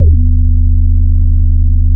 SPACE SUB 1.wav